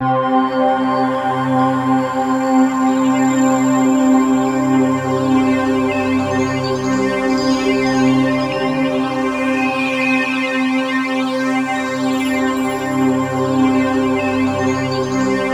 TUBULARC3.-R.wav